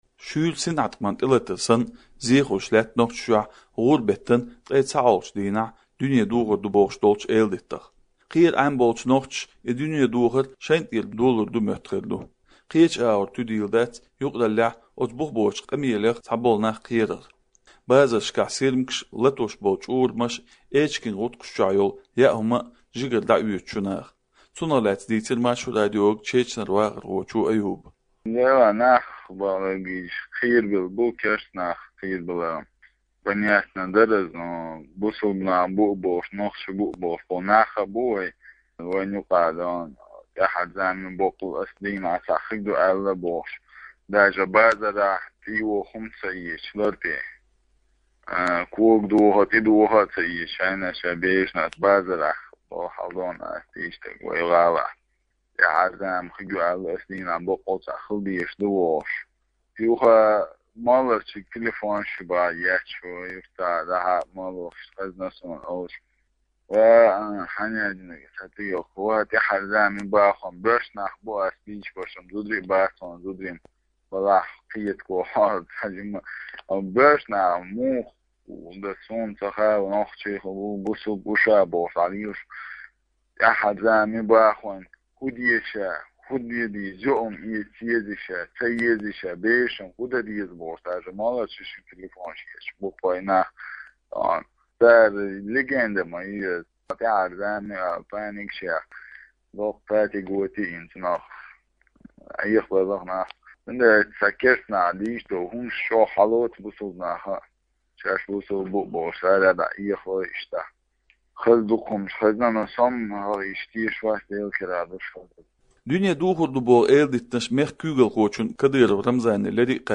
Эрчадаьккхина нохчийн хелхар